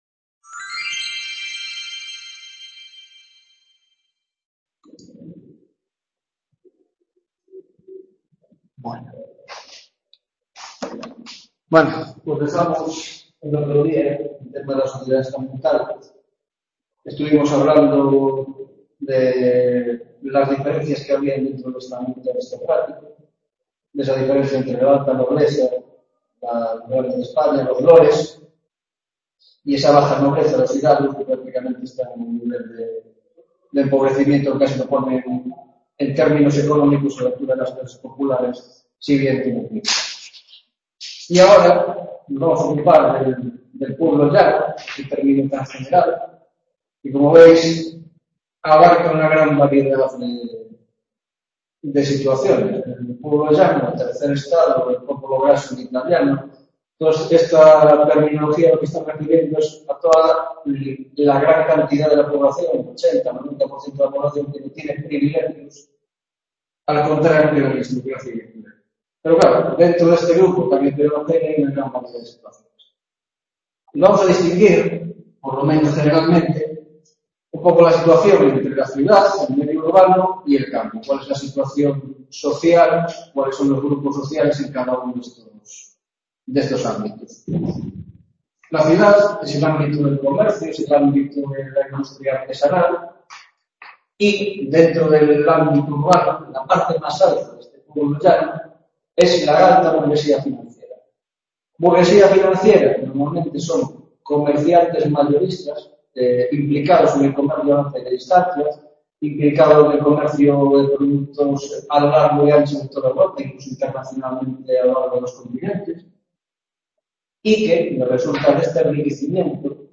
3ª Tutoría de Historia de la Alta Edad Moderna - Sociedad Estamental y Economía